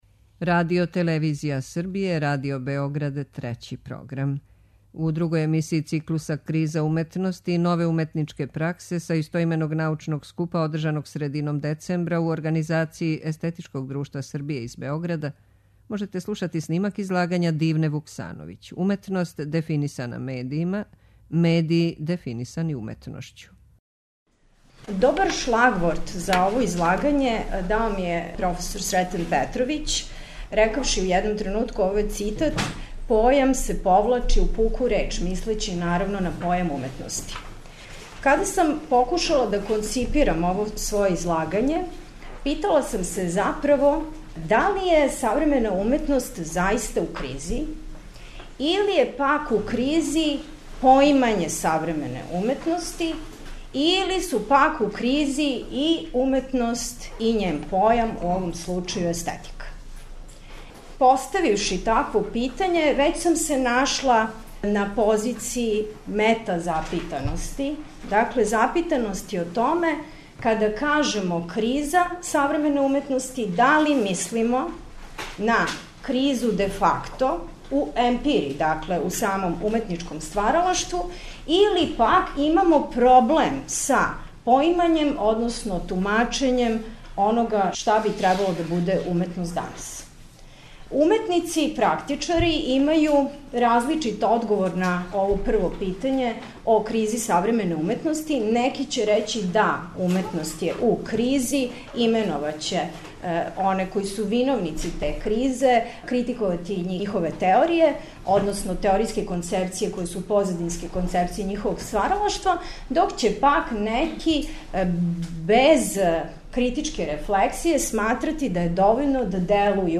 У две вечерашње емисије, којима почињемо циклус КРИЗА УМЕТНОСТИ И НОВЕ УМЕТНИЧКЕ ПРАКСЕ, можете пратити снимке излагања са истоименог научног скупа одржаног средином децембра у организацији Естетичког друштва Србије.
Научни скупови